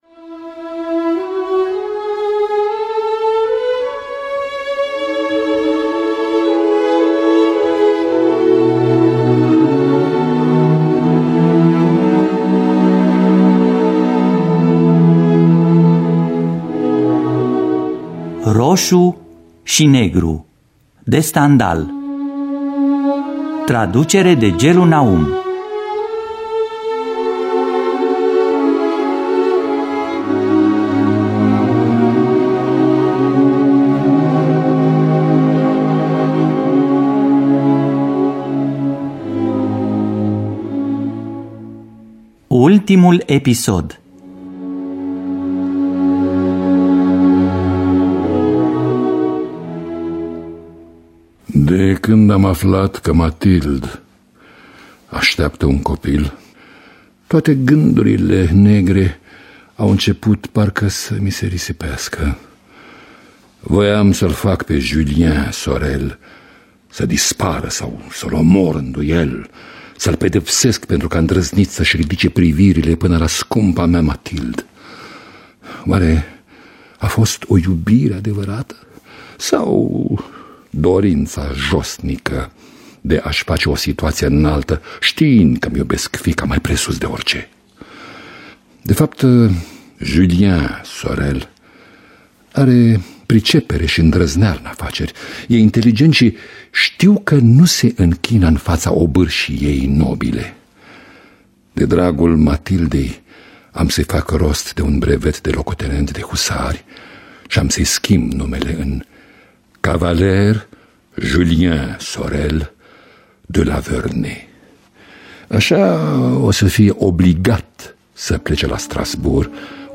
Stendhal – Rosu Si Negru (2006) – Episodul 6 – Teatru Radiofonic Online